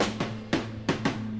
Chopped Fill 7.wav